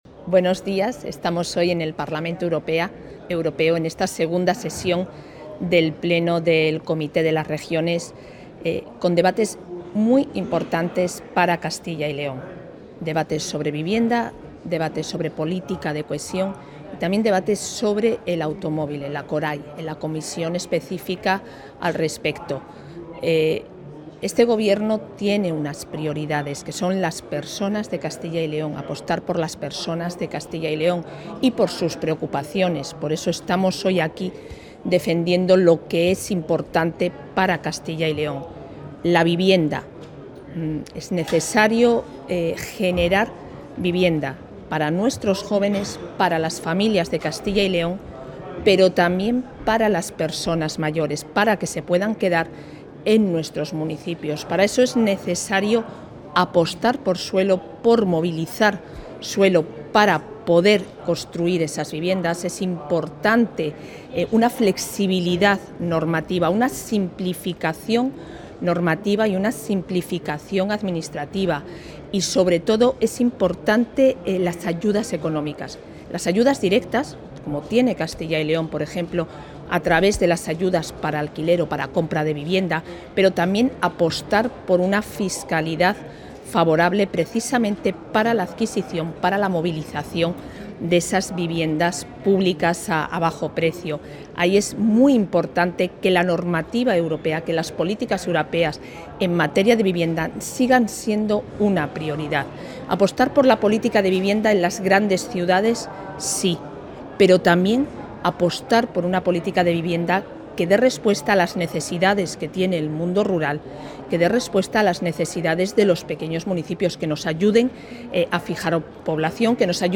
La vicepresidenta de la Junta, Isabel Blanco, ha intervenido hoy en el pleno del Comité Europeo de las Regiones, donde ha trasladado las...
Declaraciones de la vicepresidenta de la Junta.